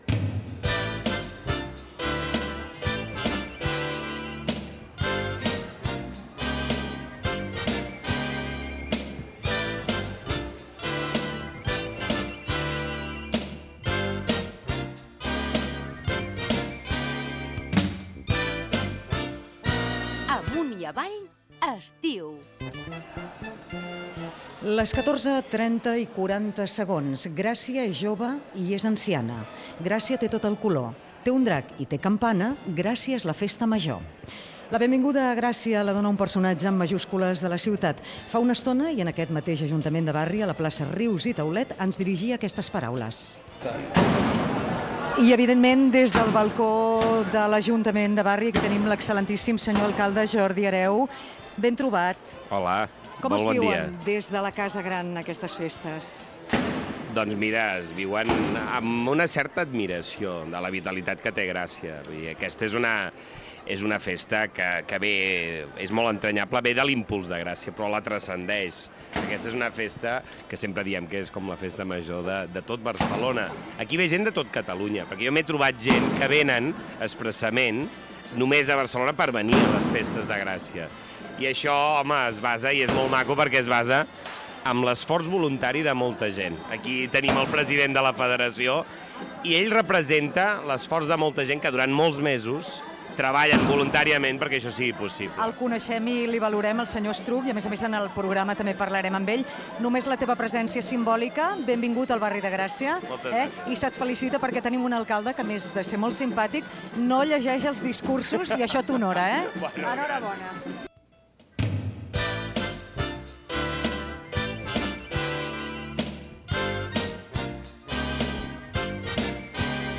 Fragment d'una edició especial des de la Festa Major de Gràcia. Indicatiu del programa, entrevista a l'alcalde de Barcelona, Jordi Hereu.